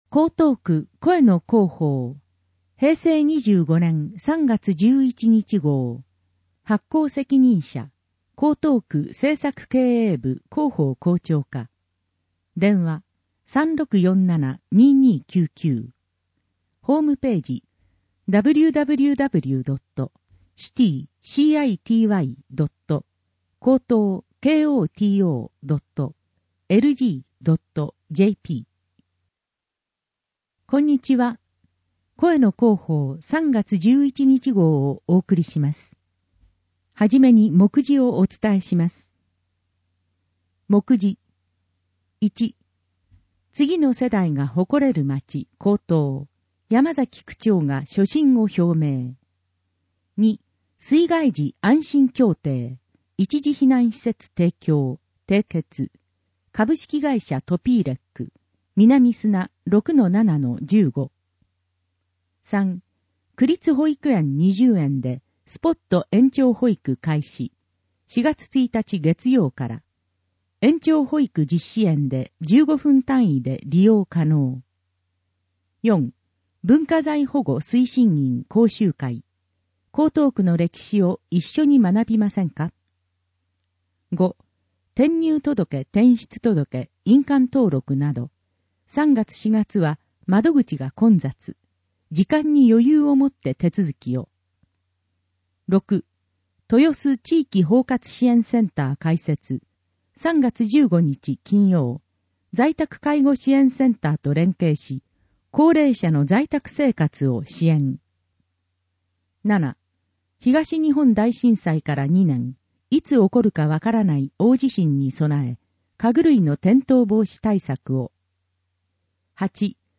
声の広報 平成25年3月11日号（1-8面）